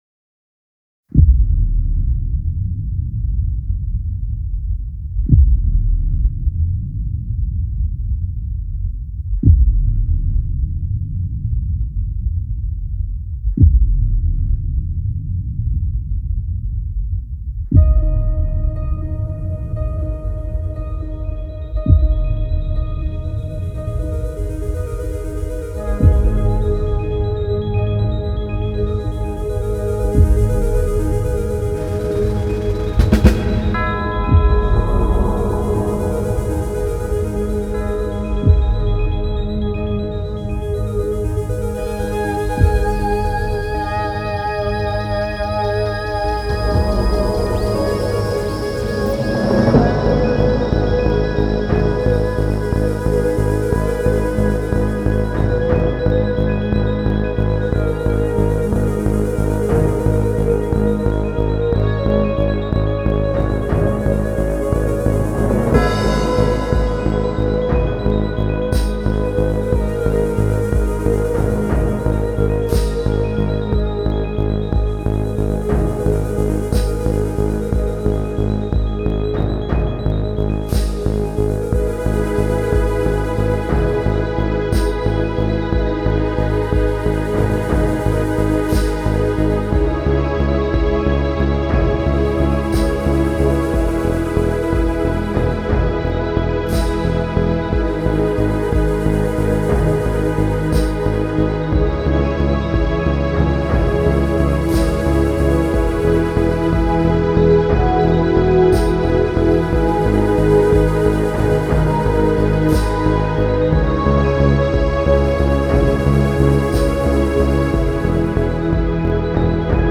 Genre: Electronic